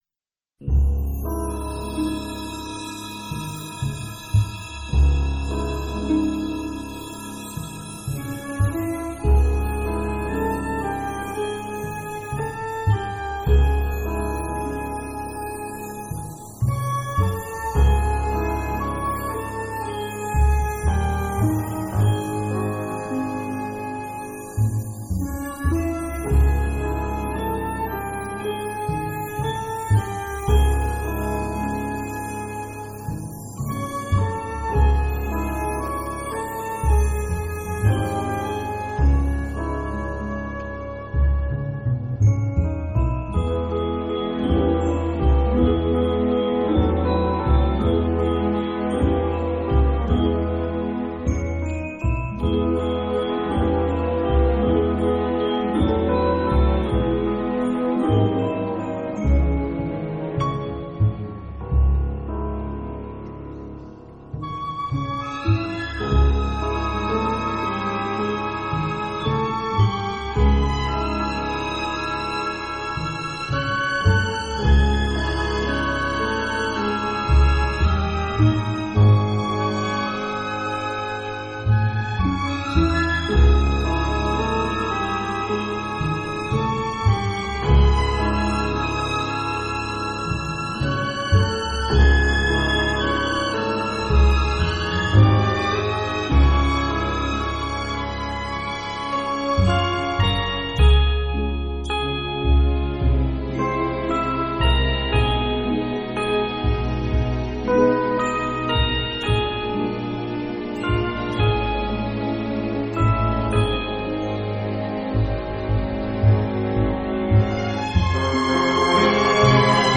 Soundtrack, Baroque, Classical